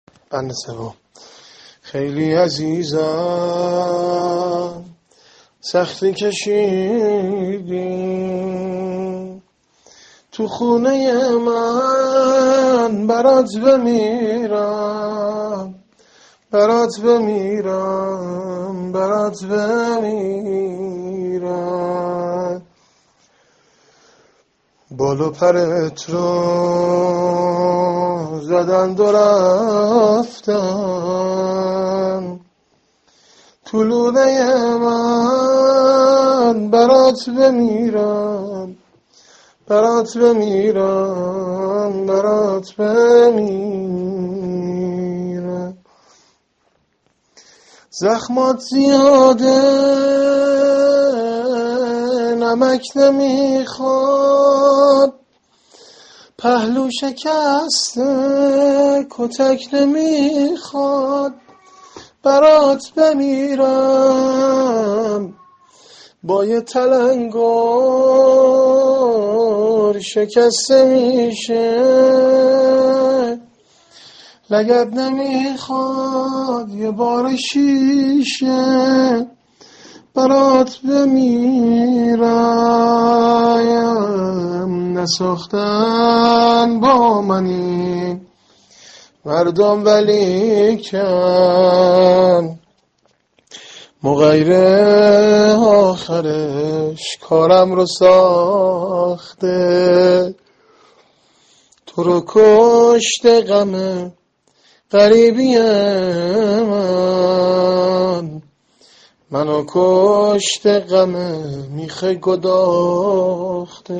زمزمه ایام فاطمیه -(می بینی خانوم ، پاشیده از هم ، یه خونواده‌، (فاطمه جانم)(۳))